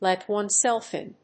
アクセントlèt onesèlf ín